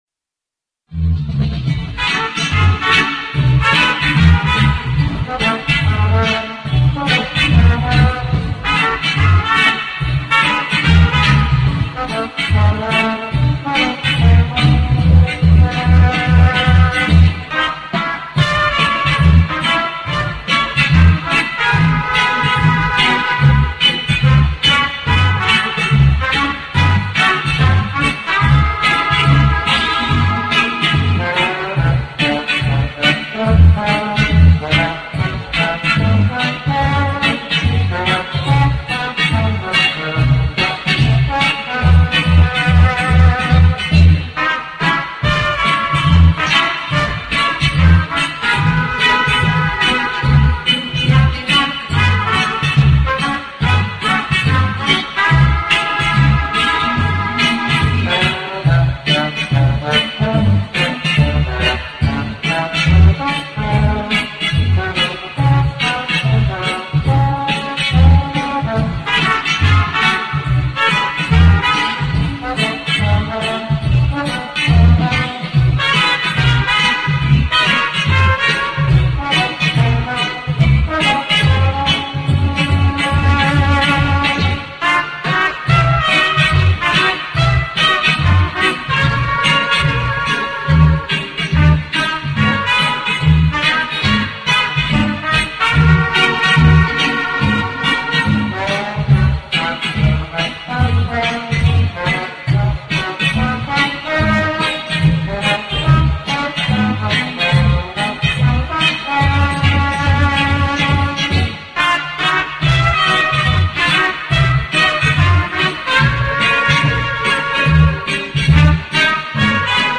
Добрый день! Помогите пожалуйста найти эту композицию в лучшем качестве, часто исполнялась по радио в 74-78г, исполнитель неизвестен название тоже.
Типично ресторанно-кабачковая оркестровка, помню на слух, раз слышал только в 2-3 часа ночи после встречи нового года.